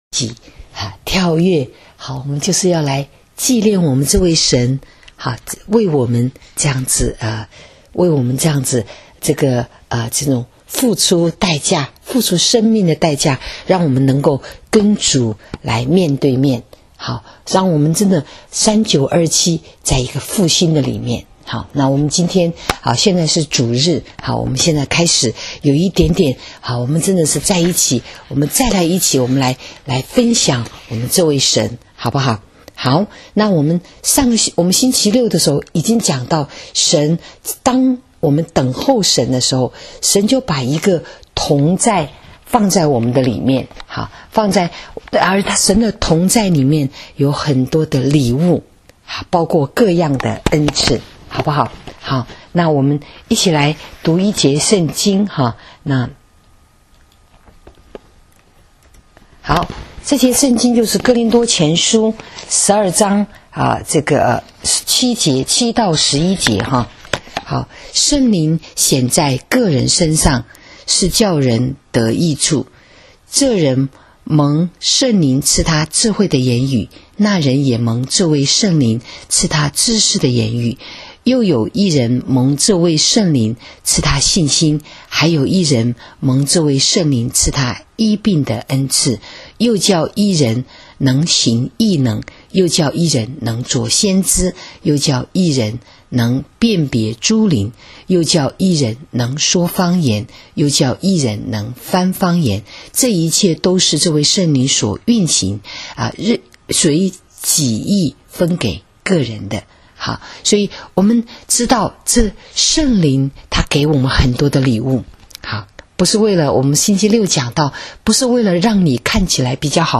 【主日信息】再谈恩赐（2）9-9-18
今天，我就讲到辨别诸灵恩赐的几个范畴，以及进深的关键…… 请点击连接，听聚会的录音。